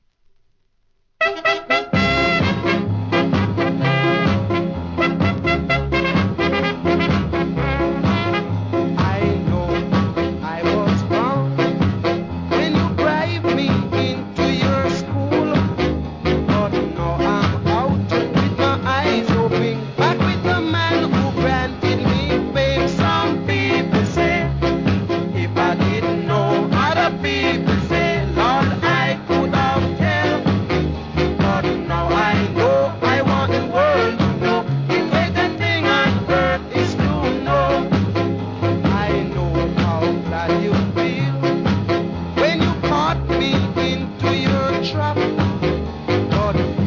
1. REGGAE
人気のVOCAL SKA!!